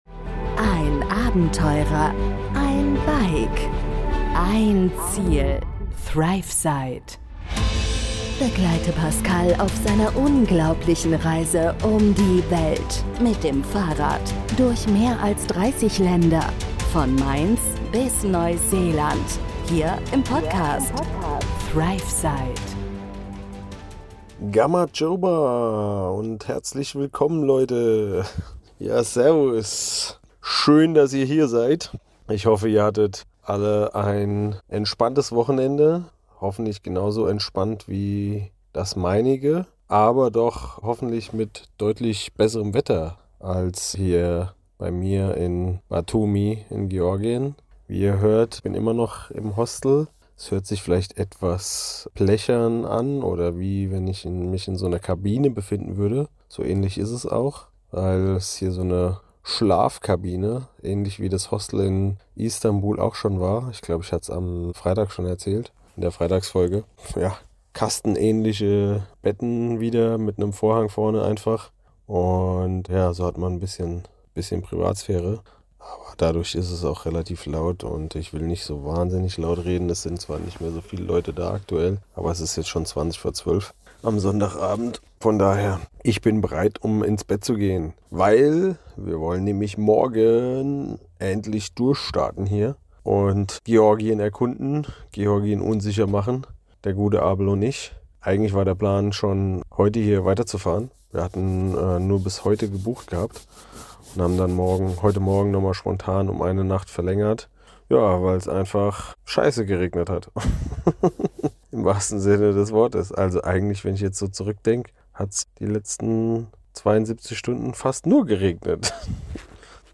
direkt aus einem Hostel irgendwo auf der Welt!